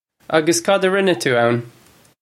Pronunciation for how to say
Uggus kad uh rinna too own?
This is an approximate phonetic pronunciation of the phrase.
This comes straight from our Bitesize Irish online course of Bitesize lessons.